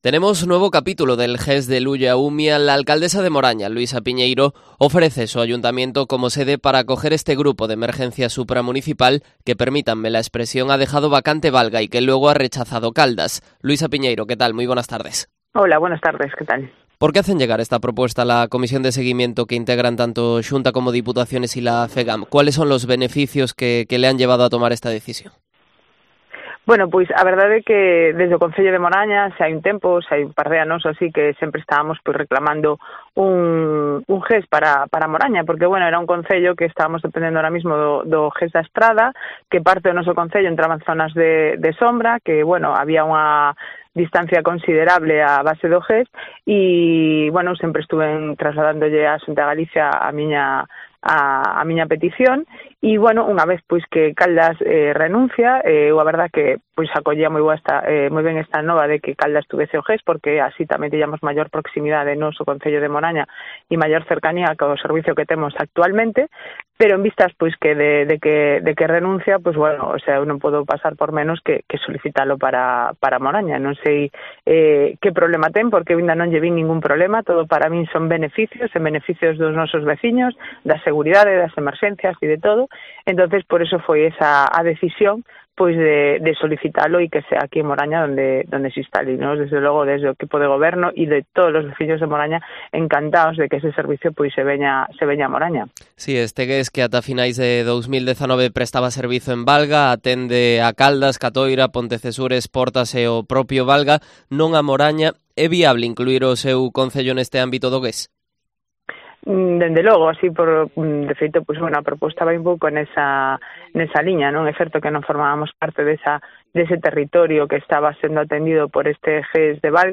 Entrevista de la alcaldesa de Moraña, Luisa Piñeiro, en Cope Pontevedra